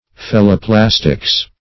Search Result for " phelloplastics" : The Collaborative International Dictionary of English v.0.48: Phelloplastics \Phel`lo*plas"tics\, n. [Gr. fello`s cork + pla`ssein to mold.] Art of modeling in cork.